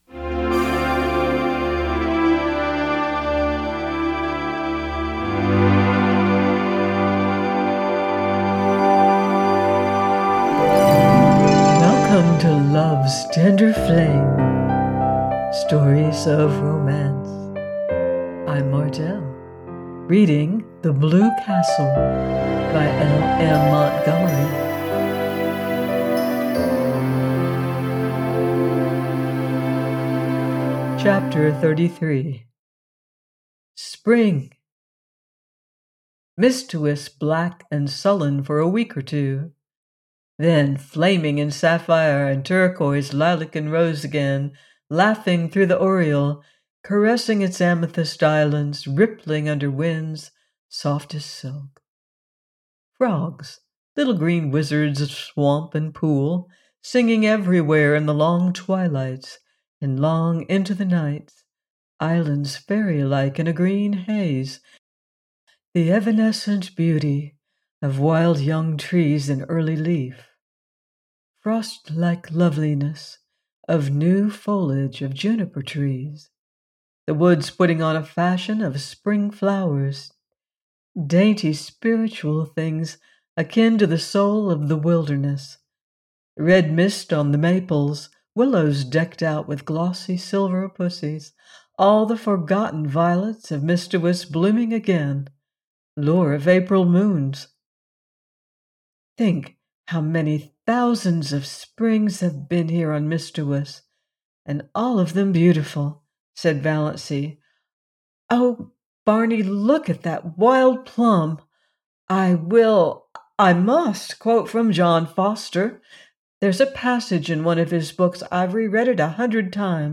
The Blue Castle by L.M. Montgomery - audiobook